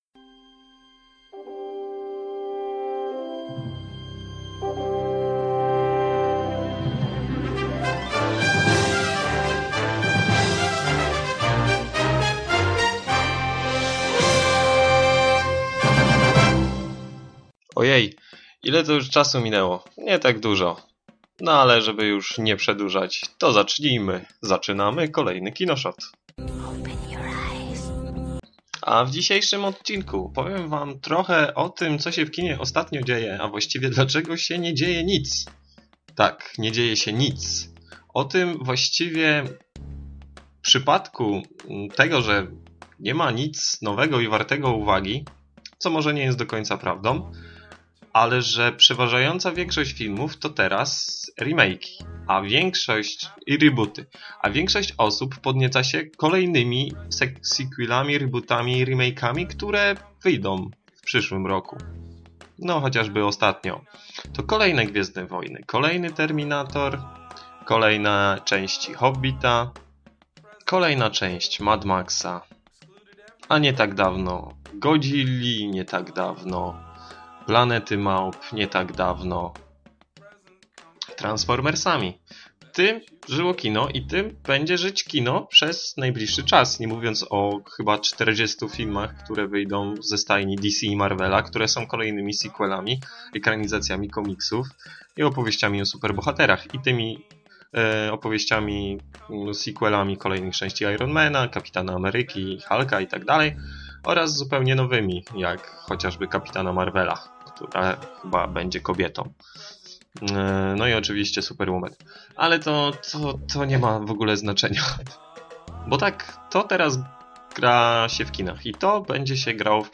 Gdzie teraz szukać dobrych filmów po ostatnich wakacyjnych odmóżdżaczach? Co się stało z ambitnymi filmami i czy w ogóle coś się z nimi stało? I zagadka – skąd jest utworek, który możecie posłuchać na końcu odcinka.